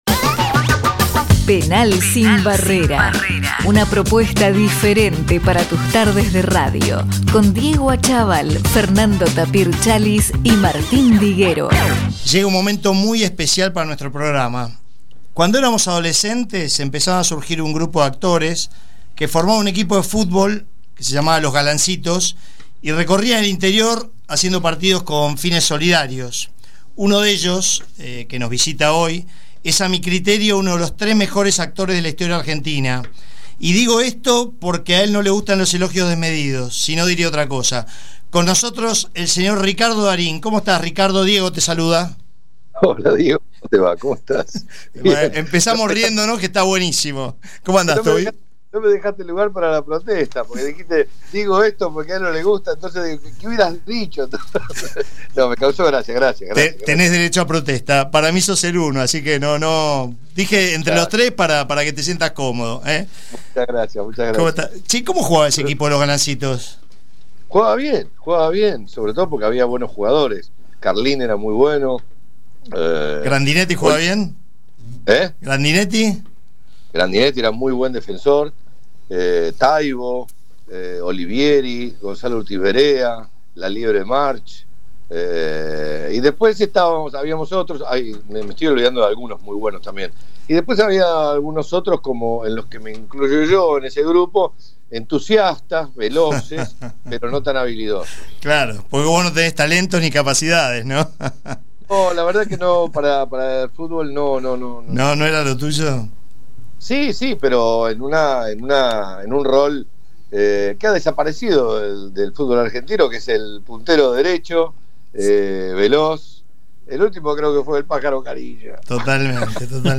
En una nueva edición de PENAL SIN VARRERA, nos dimos el lujo de charlar con uno de los mejores actores de la argentina, el señor RICARDO DARIN.